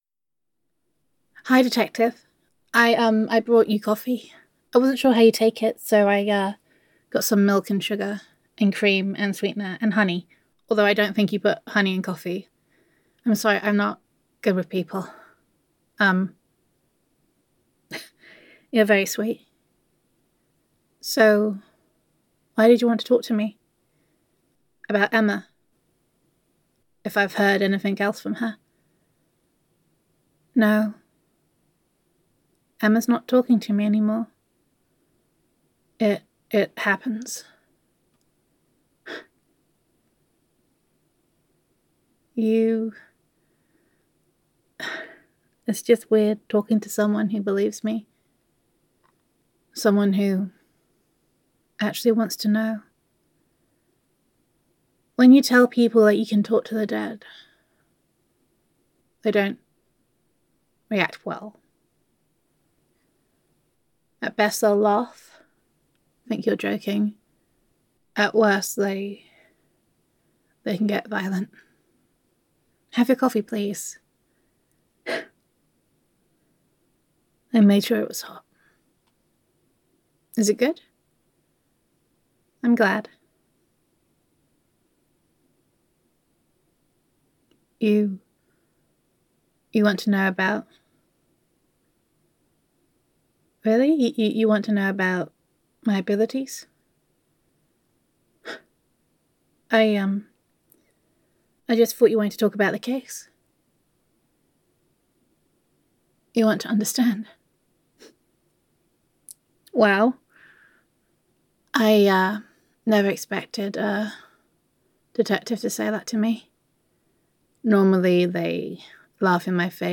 [F4A] Talking to the Dead [Medium Roleplay]